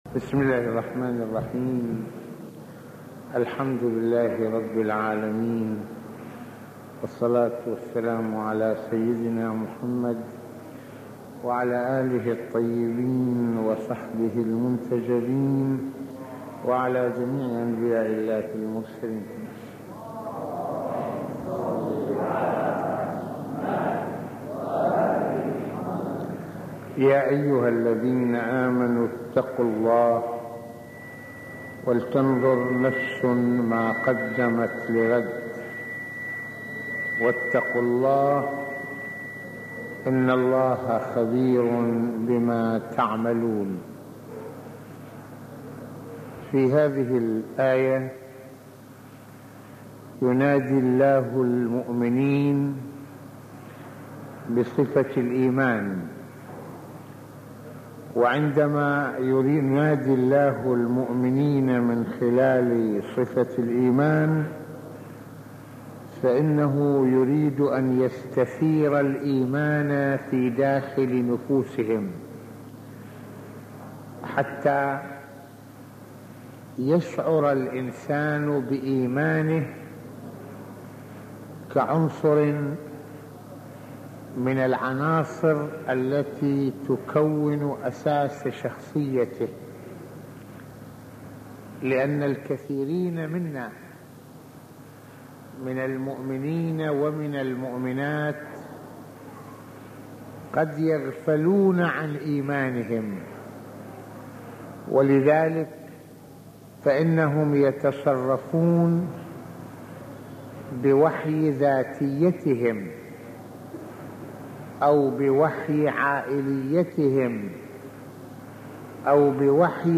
- يتحدث سماحة المرجع السيد محمد حسين فضل الله (رض) في هذه المحاضرة القرآنية عن قيمة الإيمان والتقوى وأثرهما في صناعة الشخصية ، مشيراً إلى فاعلية التقوى في إبراز جوهر الإيمان وصولا إلى نيل رضوان الله في الدنيا والآخرة ، حيث التقوى تدفع الإنسان دفعا إلى التفكير بمصيره الأخروي عبر تصحيح مساره كل حين ...